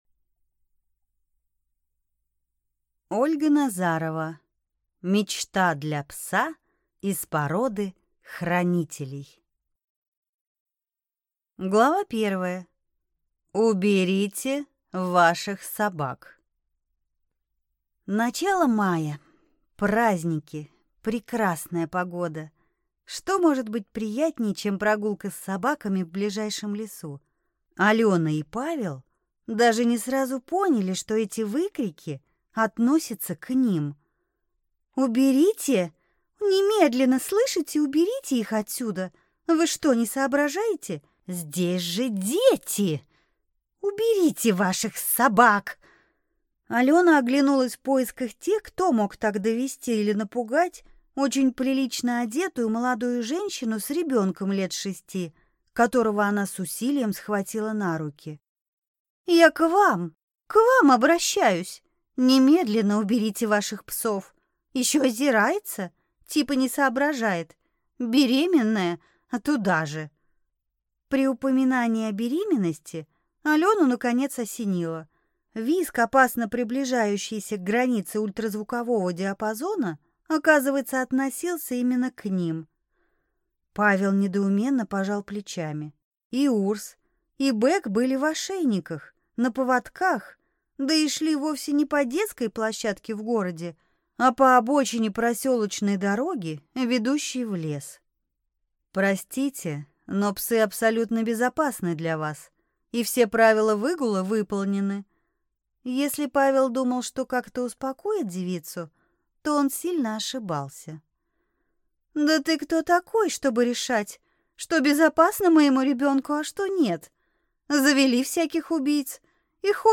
Аудиокнига Мечта для пса из породы хранителей | Библиотека аудиокниг